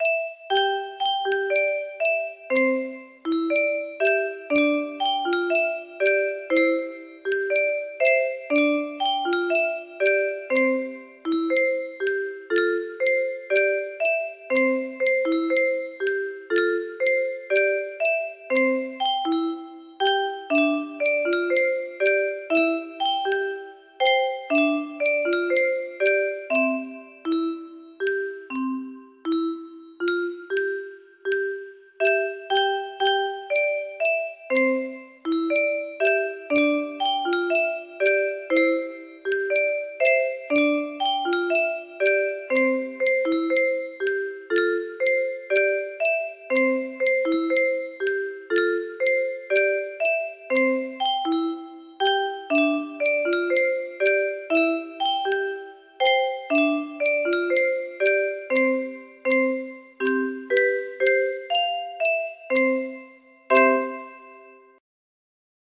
Cublak cublak suweng music box melody